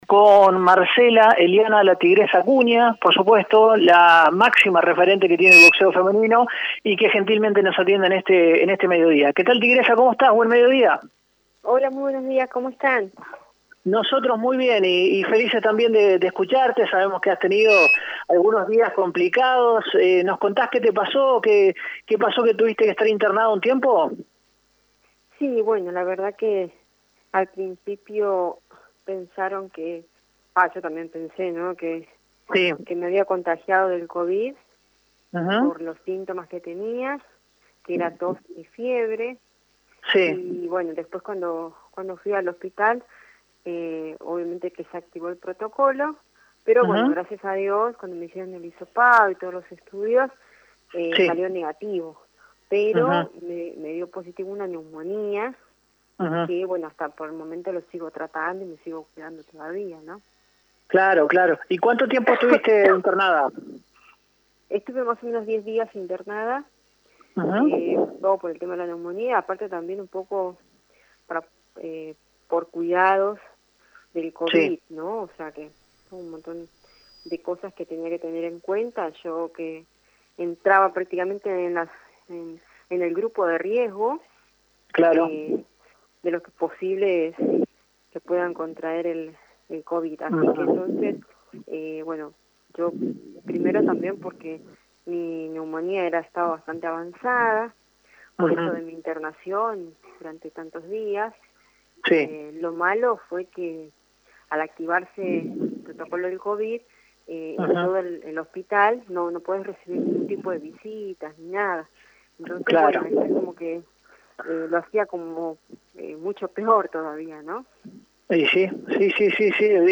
Este viernes, el equipo de Radio EME Deportivo dialogó con la boxeadora argentina Marcela «Tigresa» Acuña. La formoseña habló sobre su presente, y la actualidad de la disciplina durante la pandemia por el Coronavirus.